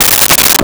Toilet Paper Dispenser 03
Toilet Paper Dispenser 03.wav